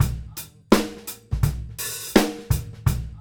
GROOVE 200JR.wav